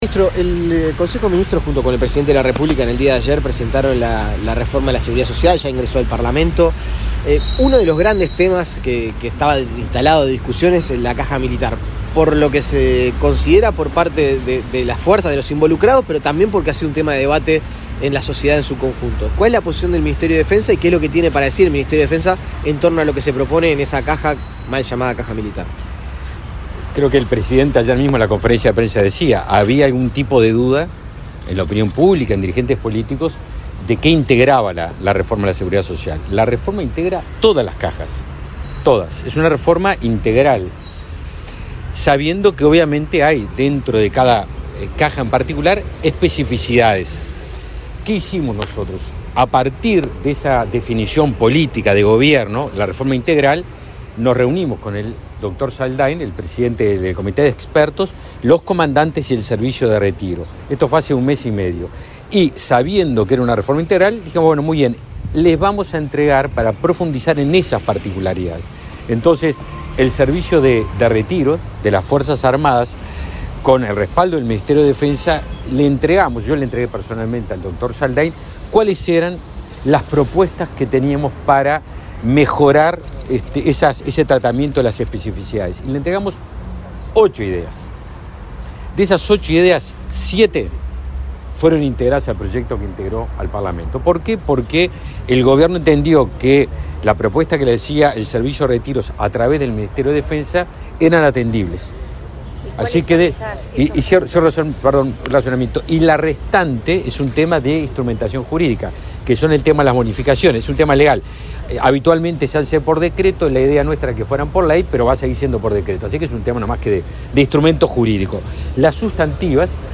Declaraciones a la prensa del ministro de Defensa, Javier García
El ministro de Defensa Nacional, Javier García, participó este viernes 21 en el acto por el aniversario del Estado Mayor de la Defensa (Esmade).